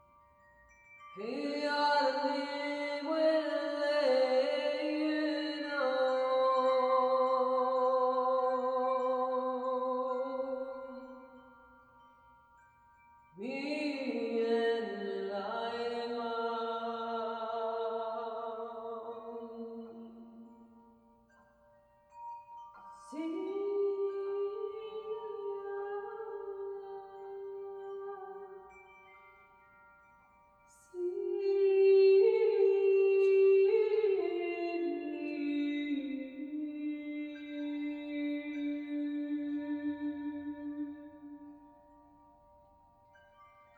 Carillons et Voix                    Durée 05:18